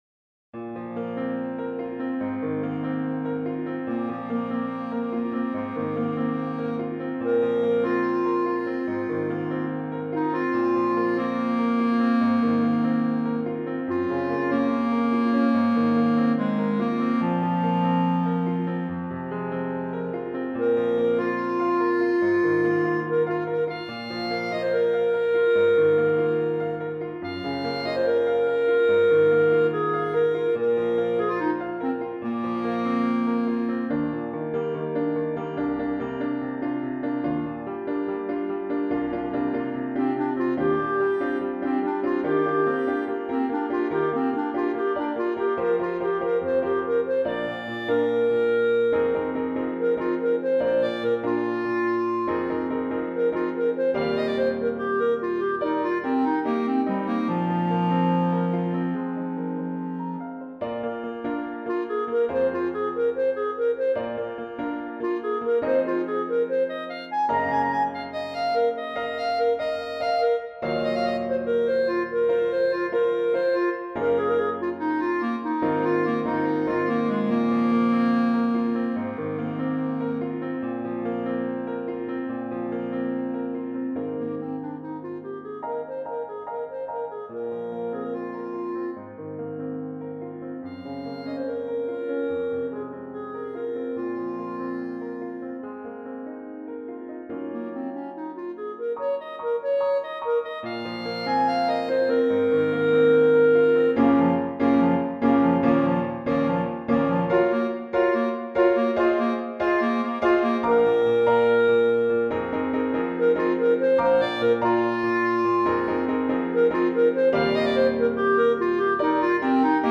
for Clarinet and Piano (2021)
The clarinet is silent at the end, bowed in prayer.